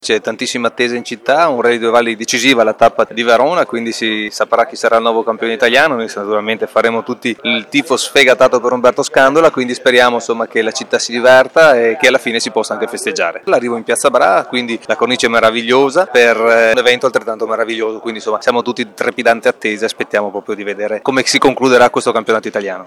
Filippo Rando assessore allo sport del Comune di Verona: